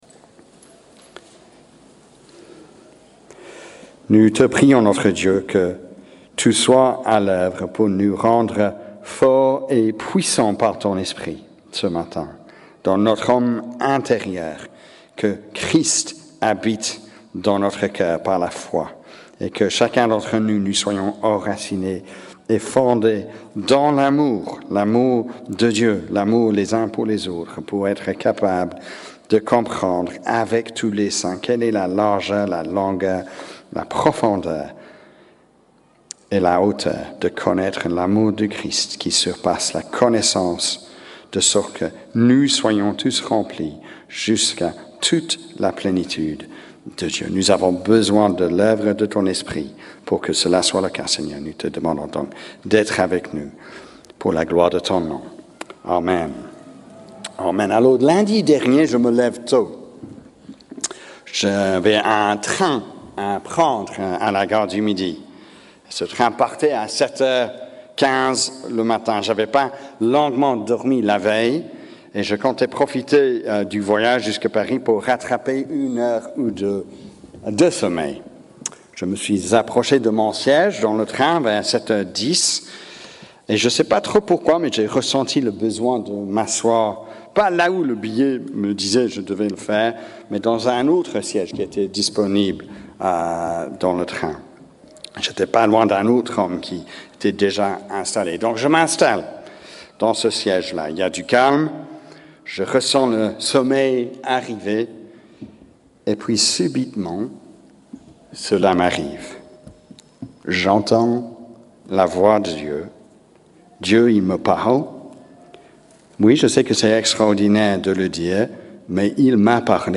Predication-10-11.mp3